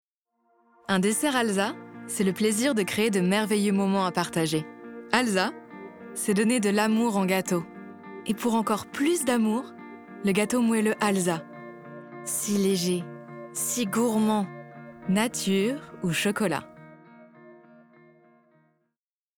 Démo voix - Pub Alsa
8 - 50 ans - Mezzo-soprano